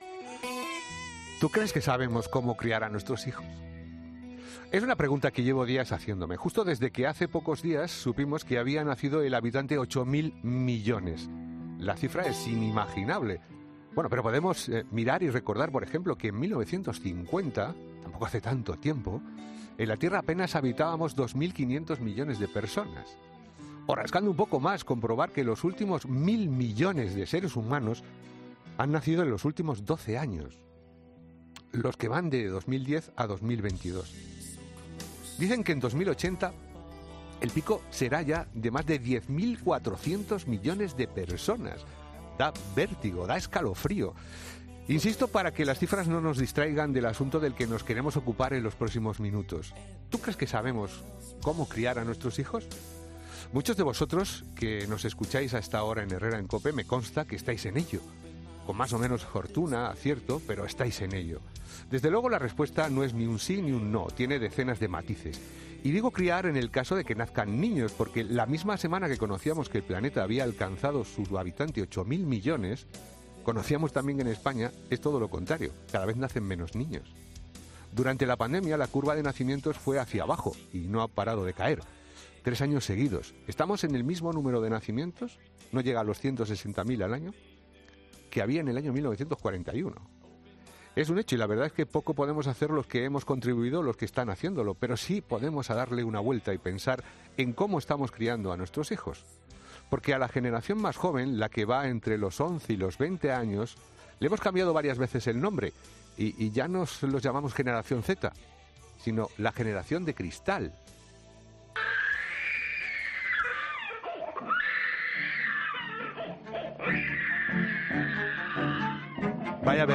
Una educadora infantil: "Un bofetón no es correcto porque solo hace que los niños obedezcan sin criterio"